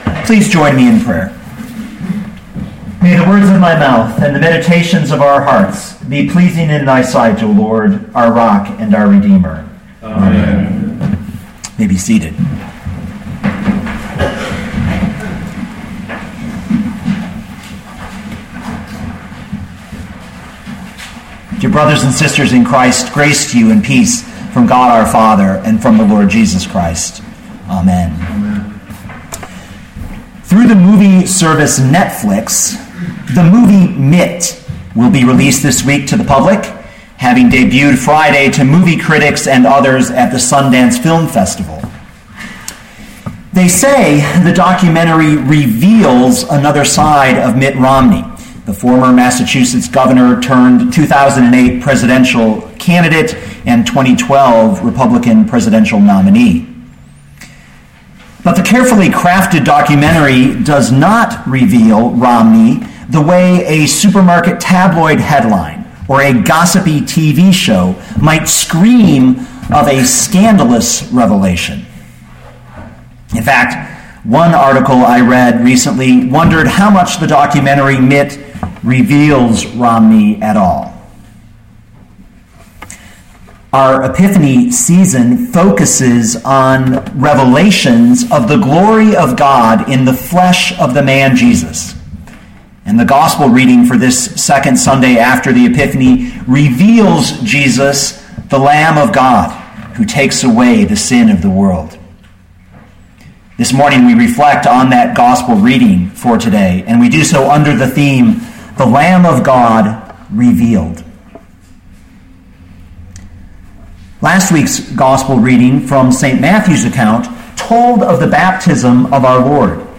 2014 John 1:29-42a Listen to the sermon with the player below, or, download the audio.